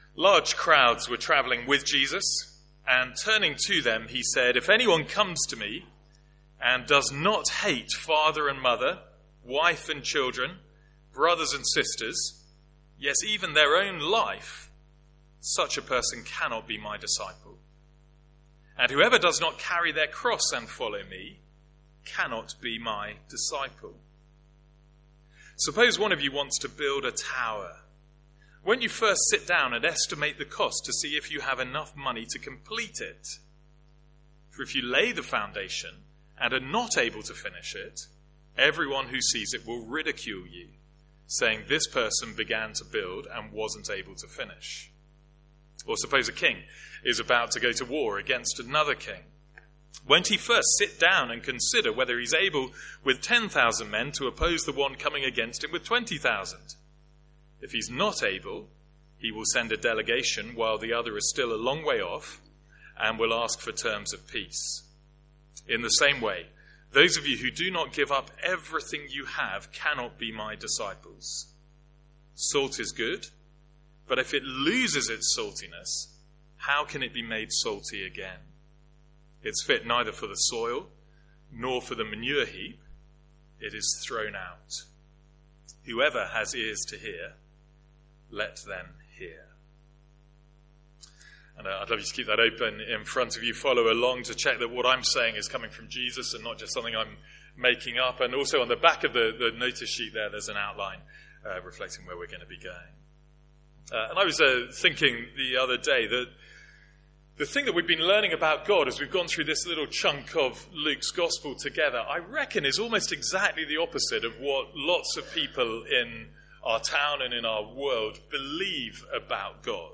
Sermons | St Andrews Free Church
From our morning series in Luke's Gospel.